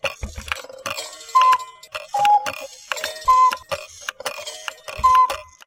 Звук сломанных часов с кукушкой
• Категория: Настенные и напольные часы с кукушкой
• Качество: Высокое